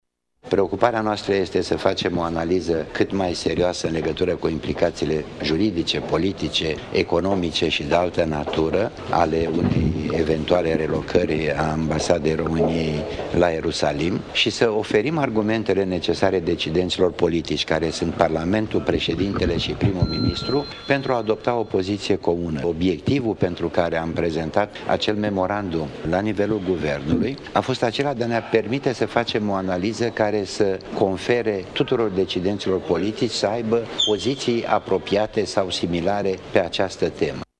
El a fost audiat ieri în Parlament pe tema acestui memorandum care a stârnit un conflict între preşedinte şi guvern.